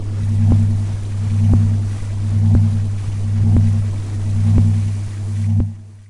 这个创作可以是洗衣机的声音效果，你可以用它来制作你的电影。
Tag: 清洁 做家务 电影 洗衣机